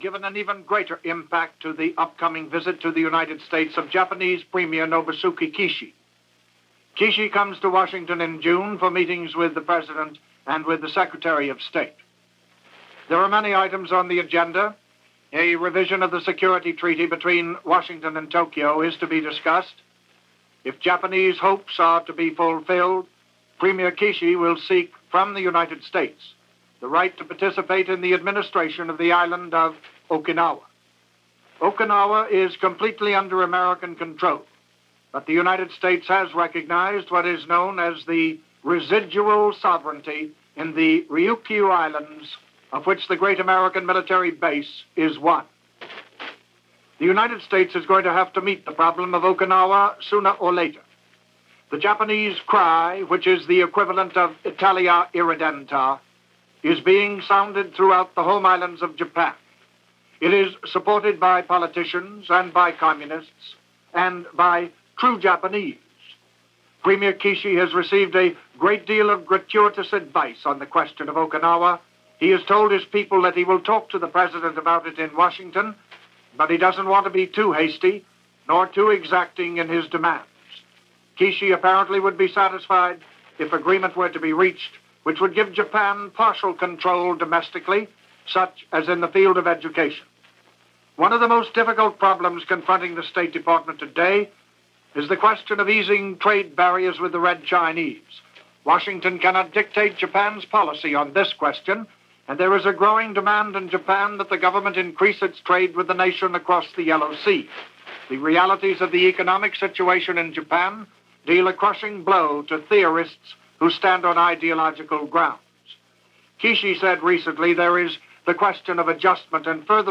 Commentary – Mutual Broadcasting System